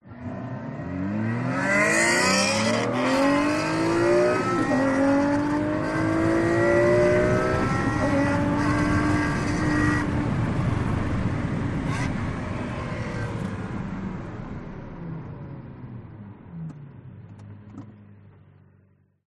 Звук разгона изнутри салона Феррари